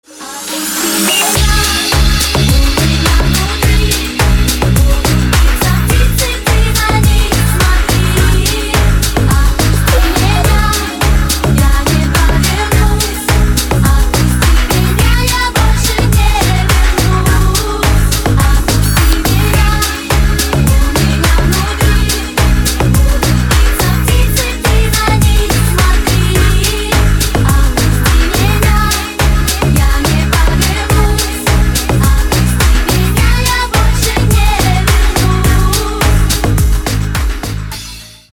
женский вокал
Club House
электронная музыка
Dance Pop